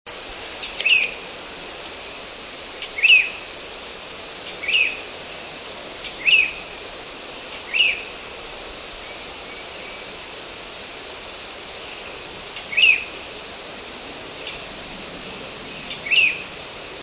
Carolina Wren
wren_carolina_691.wav